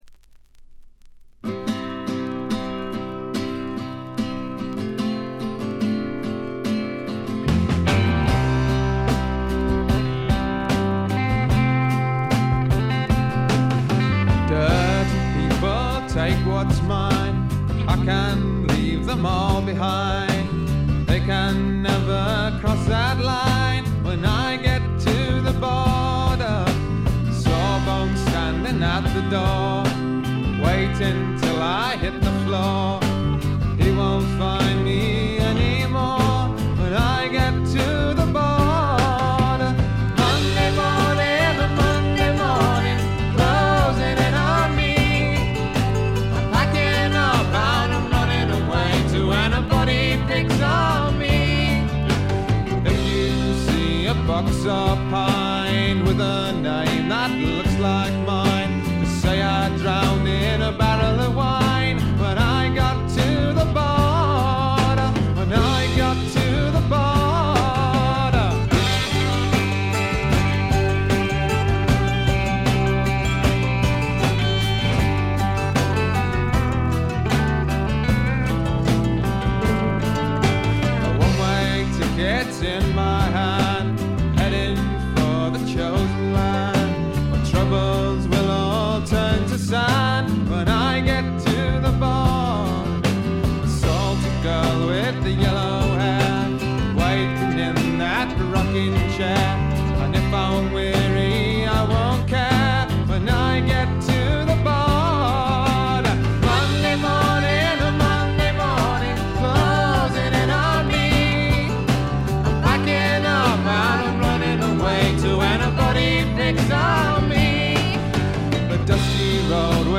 部分試聴ですが、ほとんどノイズ感無し。
アコースティック･パートの静謐な美しさ、あふれんばかりロッキン魂でドライヴする天才的なエレクトリック・ギター。
英国のフォークロックはこう来なくっちゃというお手本のようなもの。
試聴曲は現品からの取り込み音源です。
guitar, vocals, dulcimer, mandolin, whistle, keyboards